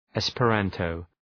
Προφορά
{,espə’ræntəʋ, ,espə’rɑ:ntəʋ}